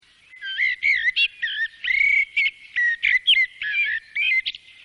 European Blackbird Between Bouts of Song
amsel.mp3